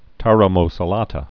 (tärä-mō-sä-lätä)